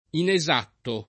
vai all'elenco alfabetico delle voci ingrandisci il carattere 100% rimpicciolisci il carattere stampa invia tramite posta elettronica codividi su Facebook inesatto [ ine @# tto ] agg. («poco preciso»; burocr. «non riscosso»)